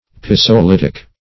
Search Result for " pisolitic" : The Collaborative International Dictionary of English v.0.48: Pisolitic \Pi`so*lit"ic\, a. [Cf. F. pisolithique.]